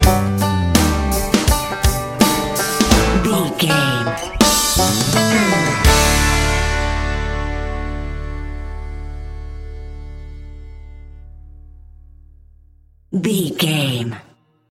Ionian/Major
D
drums
electric piano
electric guitar
bass guitar
banjo
Pop Country
country rock
bluegrass
happy
uplifting
driving
high energy